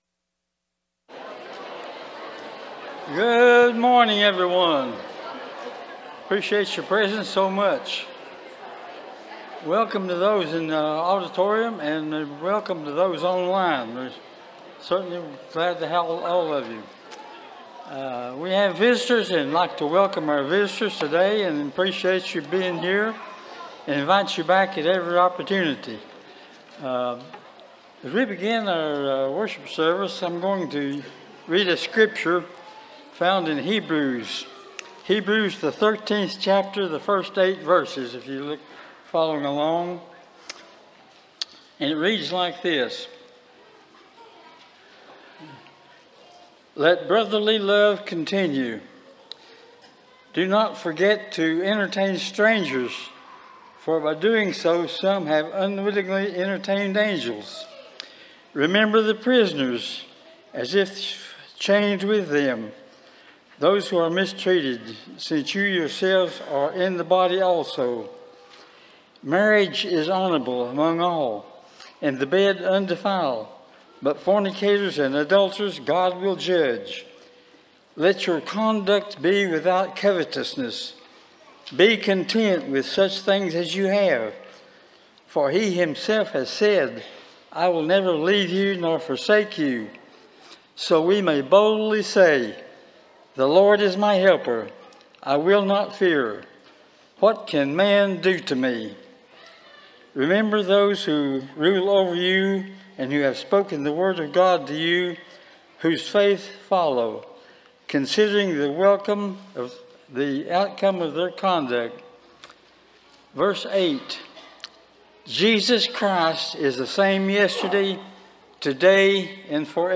Psalm 34:15, English Standard Version Series: Sunday AM Service